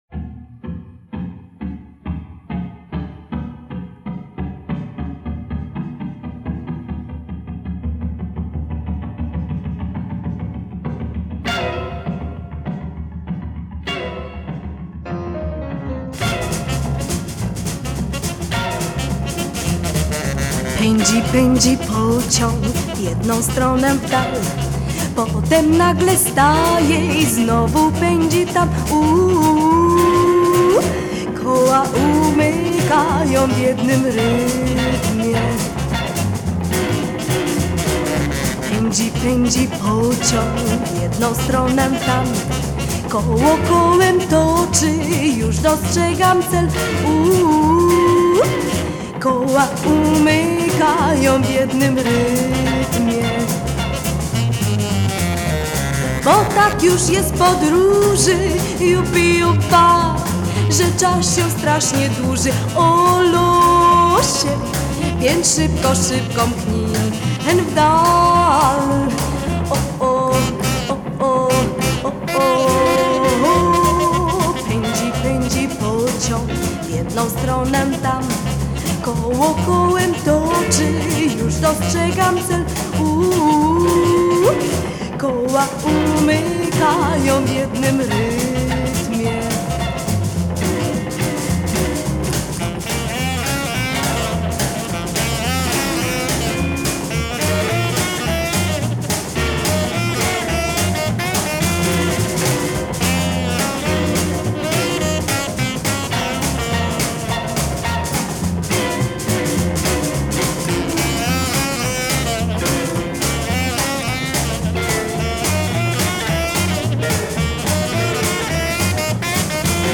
Genre: Retro, Twist, 60s, Female Vocal